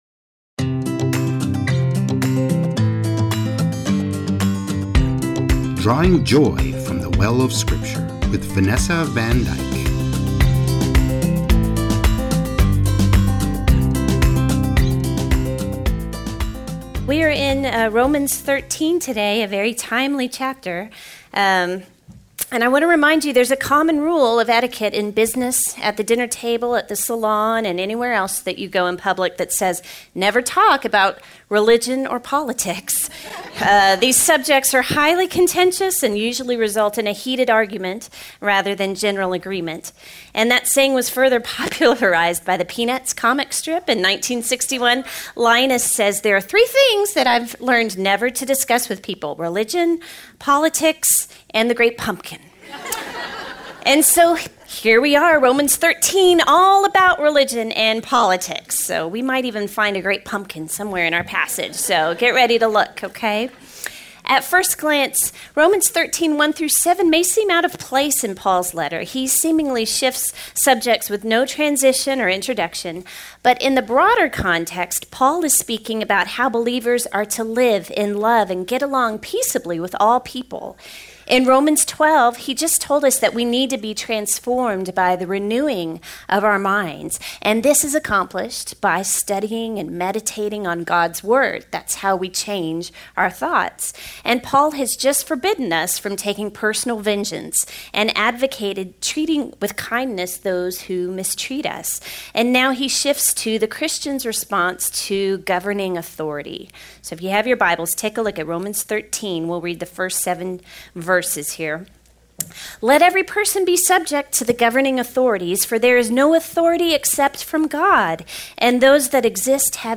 Rom-Lesson-9_mixdown.mp3